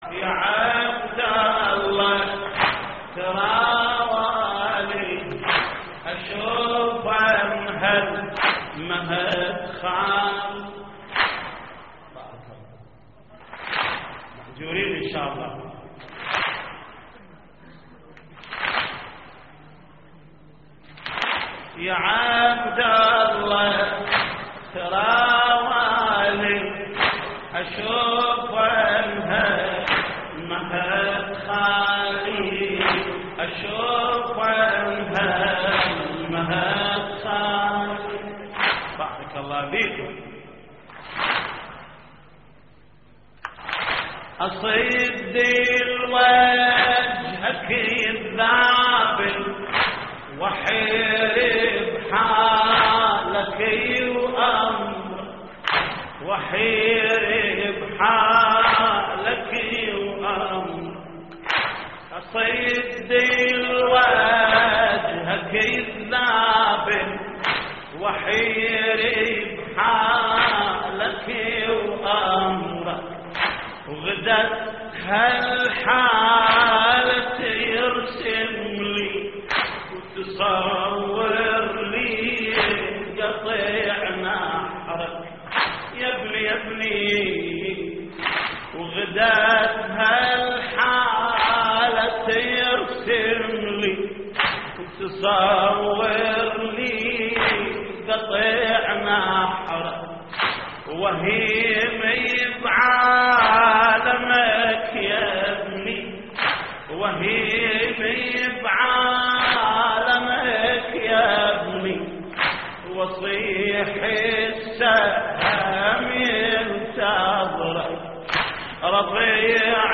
تحميل : يا عبد الله ترى والي أشوفن هالمهد خالي / الرادود جليل الكربلائي / اللطميات الحسينية / موقع يا حسين